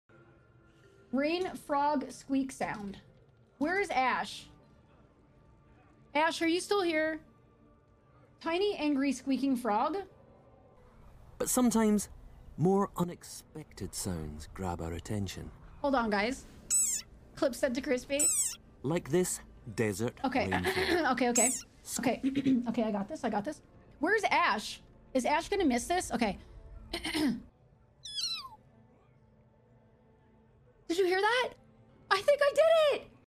I think I nailed this frog sound though!